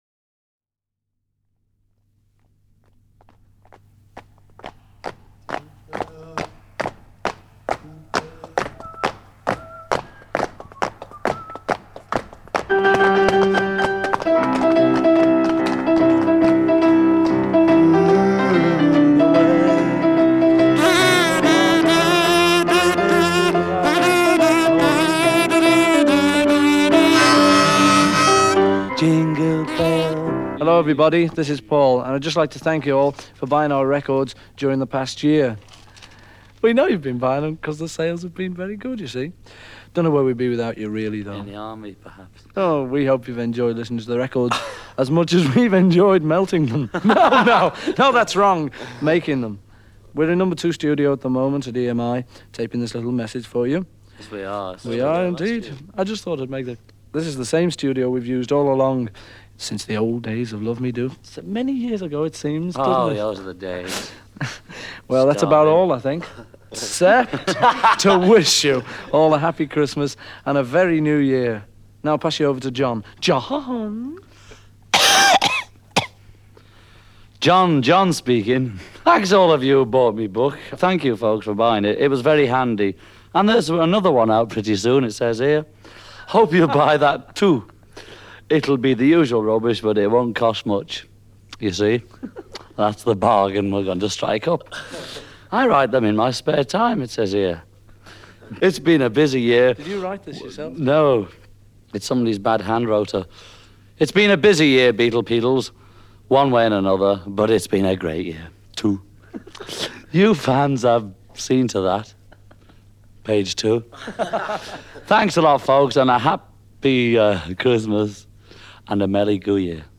1964 saw the Fab Four releasing their second recorded message to fans. The UK fan club got this message, while US fans got an edited version of the previous year’s message.